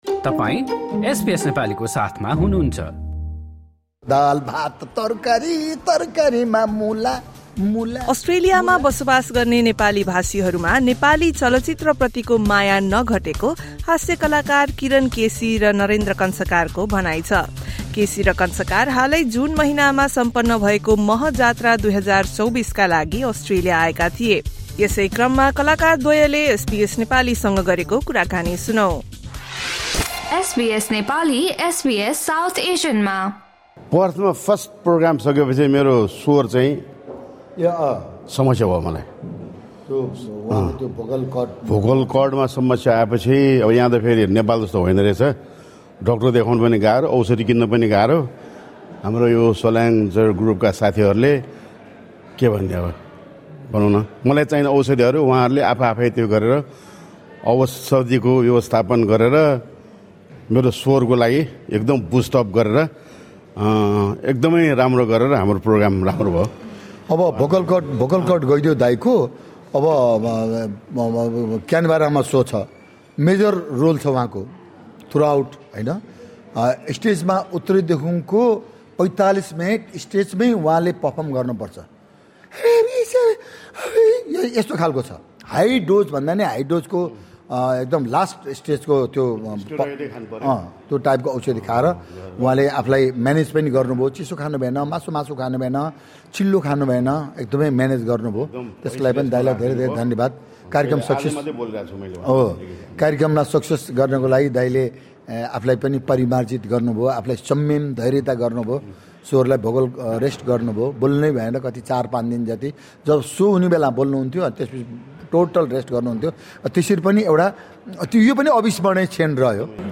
Nepali artists Kiran KC and Narendra Kansakar completed the Maha Jatra Australia/New Zealand 2024 tour in June. Best known for their comedy, the duo spoke to SBS Nepali during their visit.